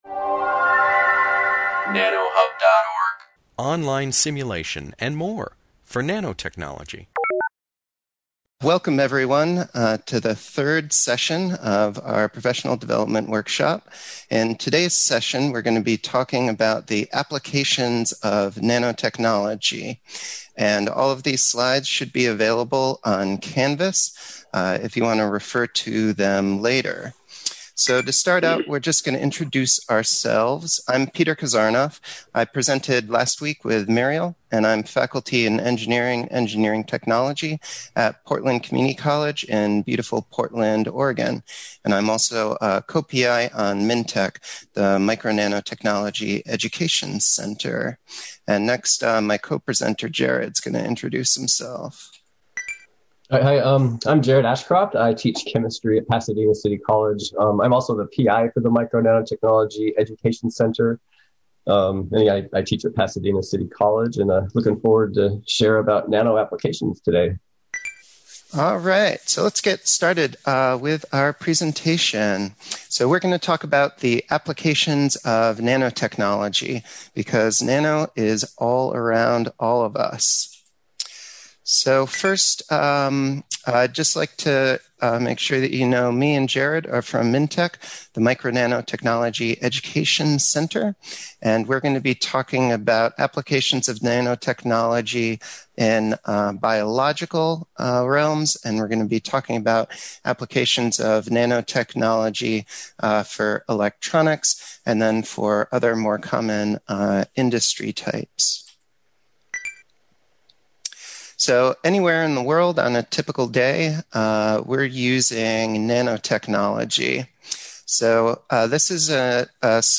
This webinar, published by the Nanotechnology Applications and Career Knowledge Support (NACK) Center at Pennsylvania State University, focuses on applications of nanotechnology, particularly in nanomedicine and nanobiology.